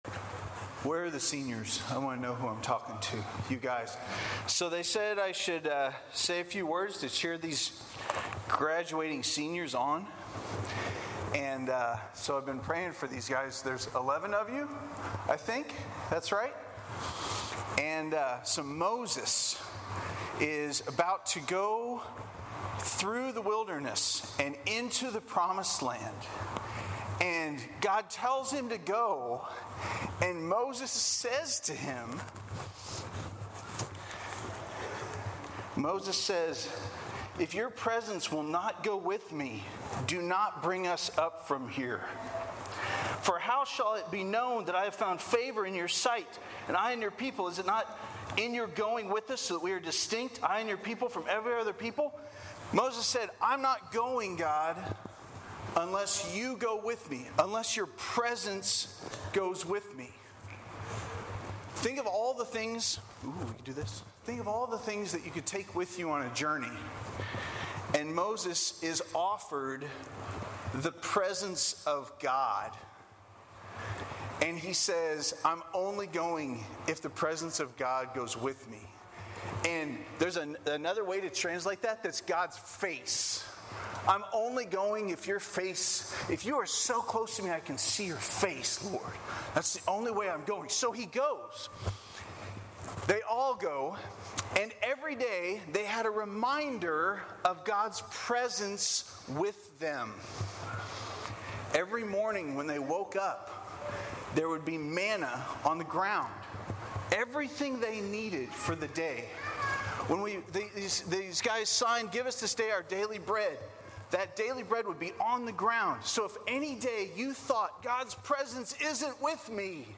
I got to speak to the graduating seniors (and their families) at Praise Ensembles final recital of the year.